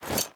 Minecraft Version Minecraft Version latest Latest Release | Latest Snapshot latest / assets / minecraft / sounds / item / armor / equip_iron6.ogg Compare With Compare With Latest Release | Latest Snapshot
equip_iron6.ogg